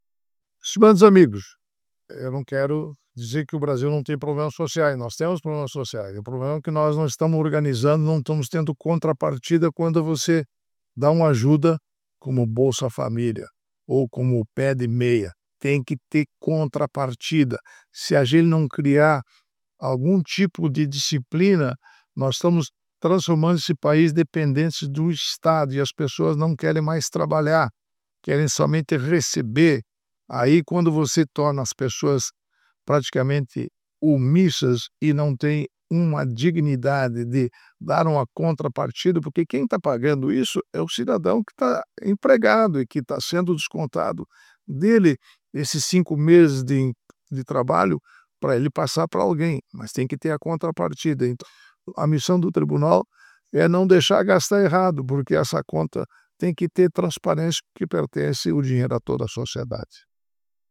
Comentário do ministro Augusto Nardes do Tribunal de Contas da União.